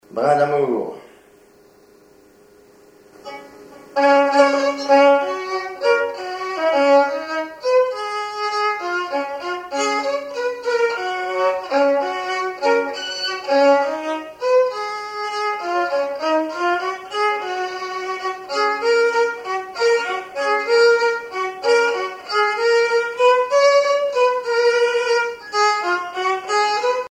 violoneux, violon
danse : valse musette
Pièce musicale inédite